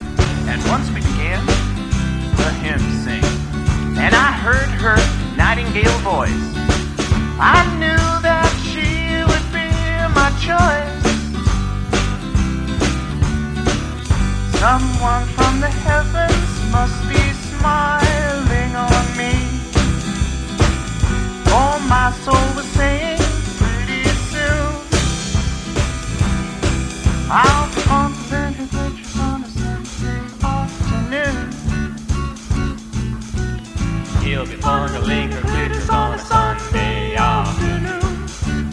gets to hide behind the ride cymbal for awhile
pretend to sing backing vocals